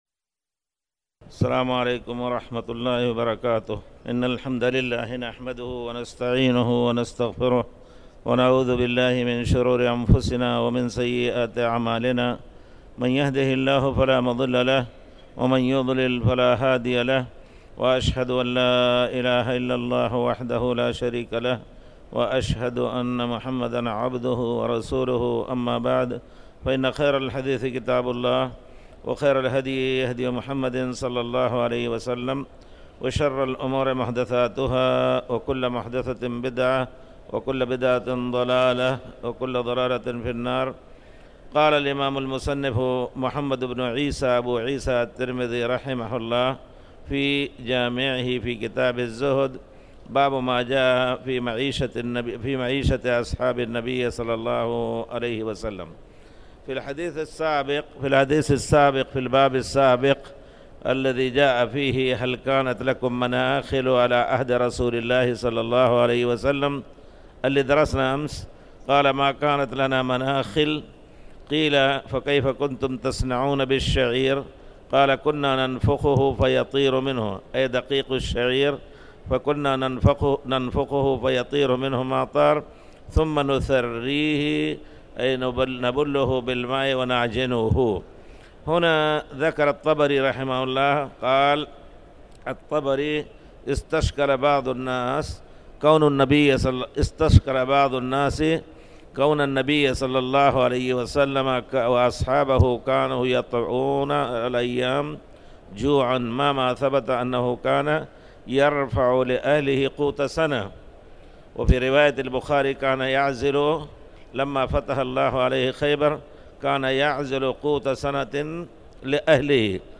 تاريخ النشر ١٧ جمادى الأولى ١٤٣٩ هـ المكان: المسجد الحرام الشيخ